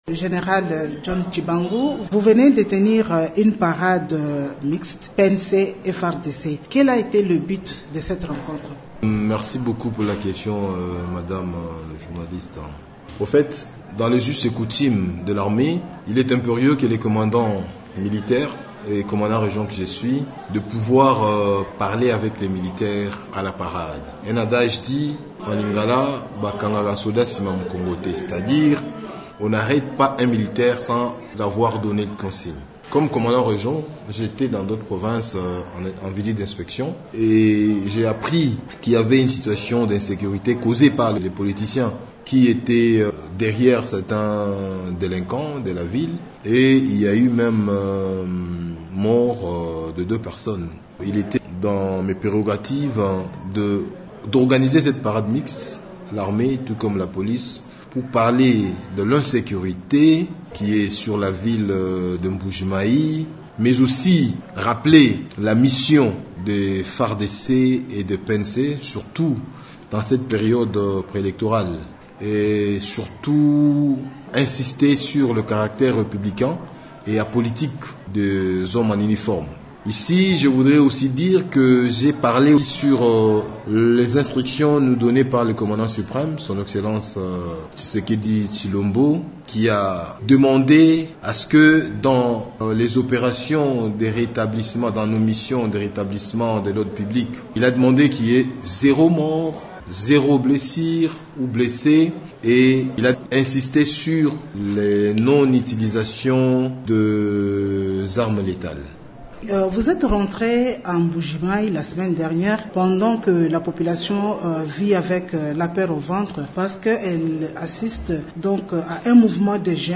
Général John Tshibangu, Commandant de 21e région militaire s’entretient à ce sujet